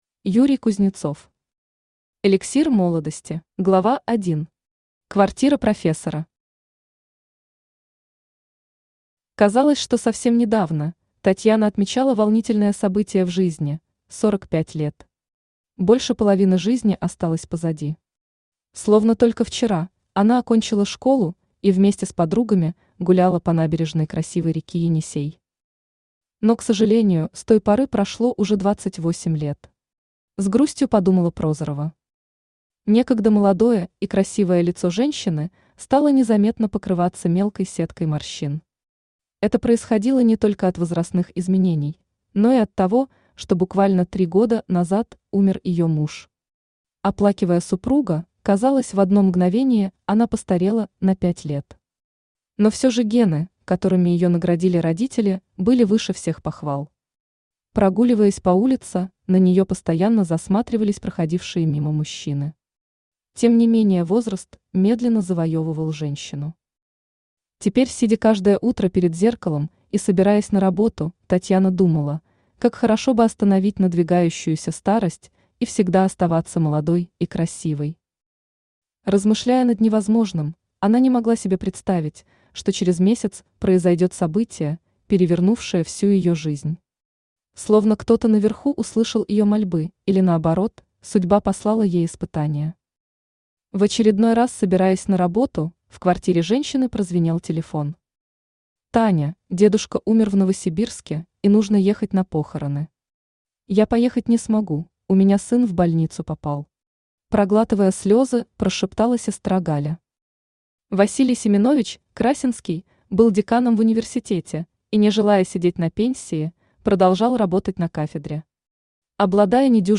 Аудиокнига Эликсир молодости | Библиотека аудиокниг
Aудиокнига Эликсир молодости Автор Юрий Юрьевич Кузнецов Читает аудиокнигу Авточтец ЛитРес.